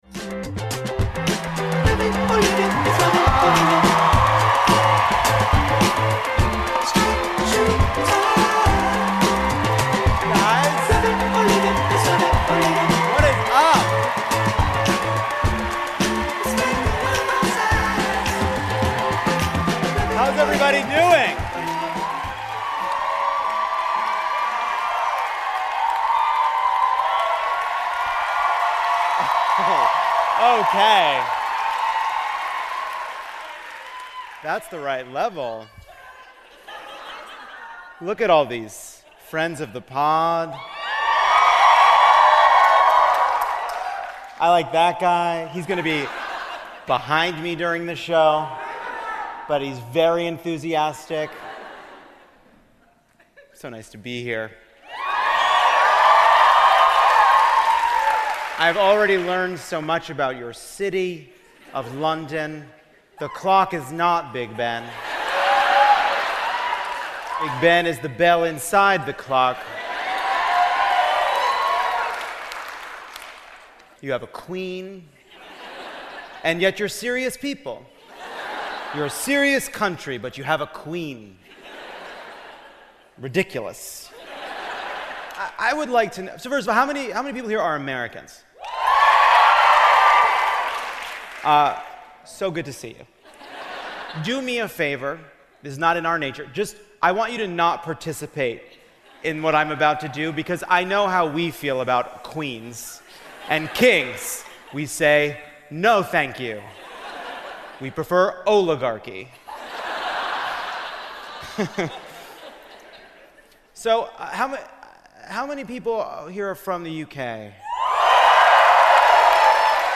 Live from London, Trump may not have felt welcome here but we sure did!